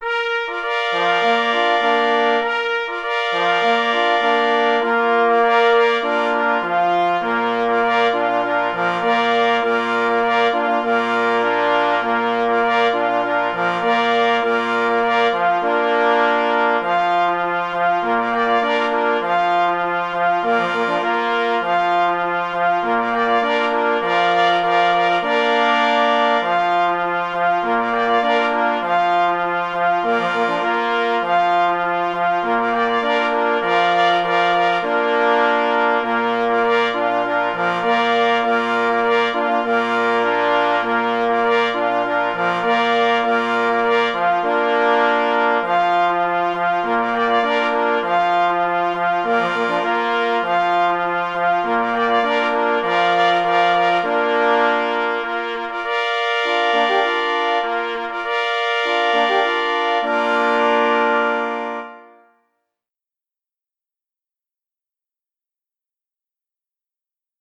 Opis zasobu: marsz 3 plesy i 2 parforsy, oparty na […]
3 plesy i 2 parforsy